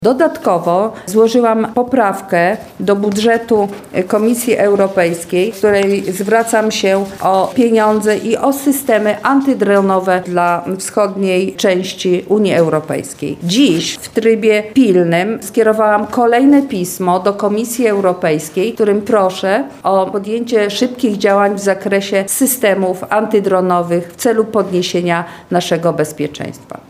– Musimy mieć tarczę, którą będziemy bronić każdego skrawka Polski – mówi europosłanka Marta Wcisło.